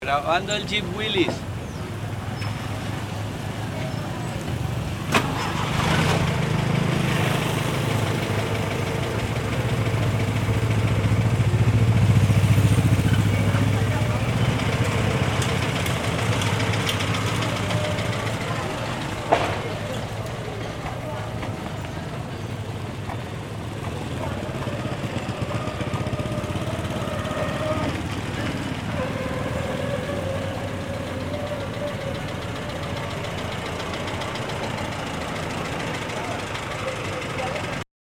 Jeep Willis
Registro sonoro del proceso de producción de café en Trujillo, Valle del Cauca. Grabación jeep.